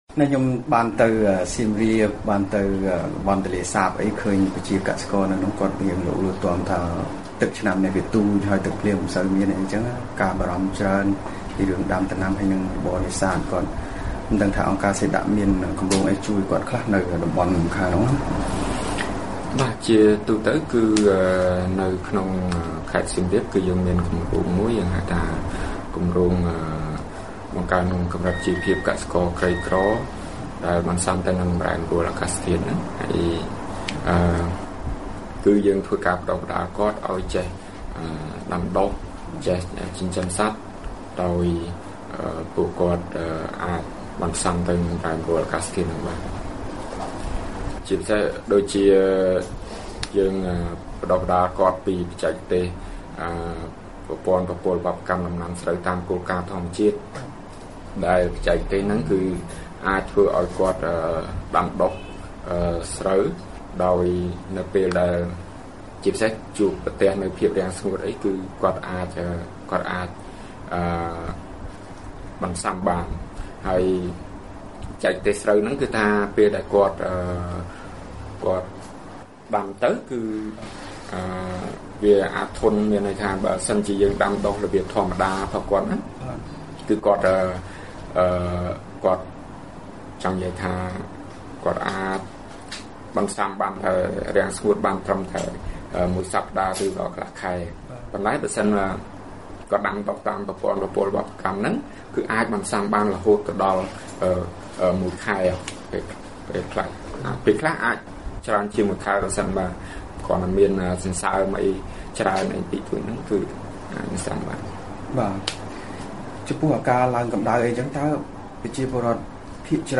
បទសម្ភាសន៍ VOA៖ ការប្រែប្រួលអាកាសធាតុប៉ះទង្គិចដល់ជីវភាពកសិករ